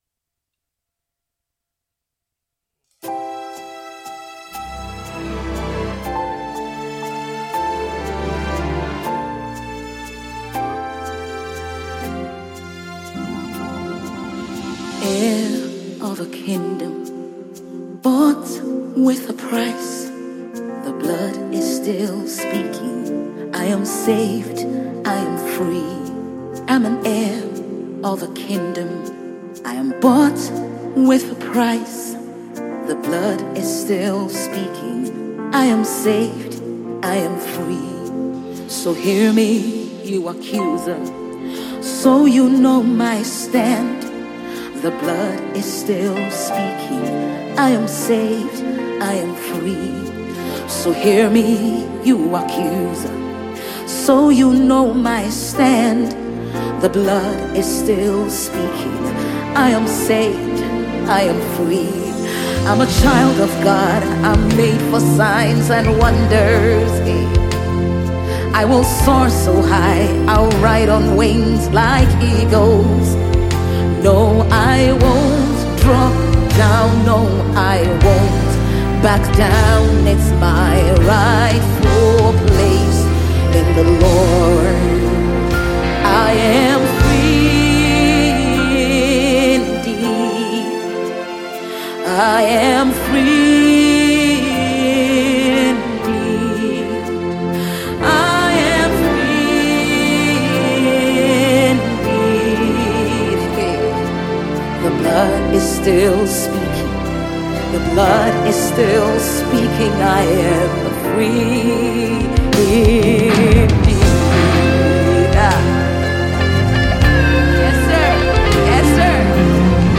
Gospel Music
gospel singer
spirit-lifting